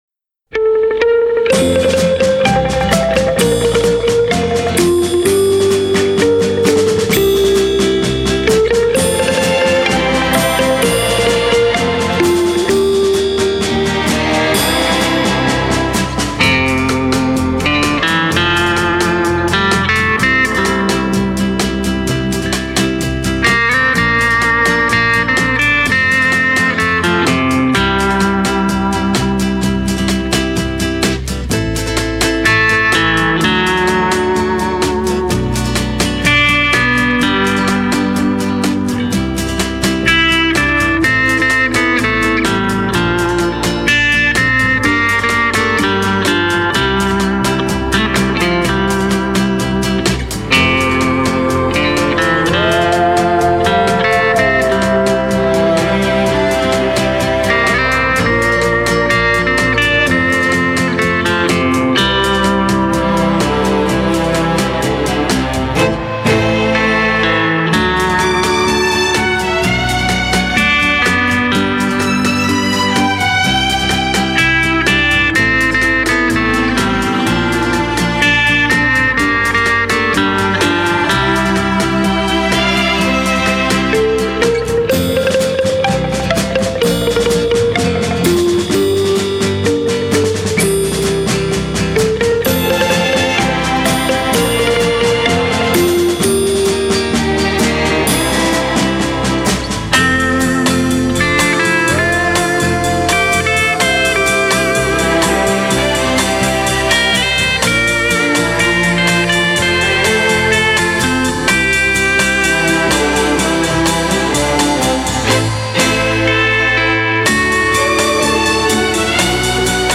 Original Mono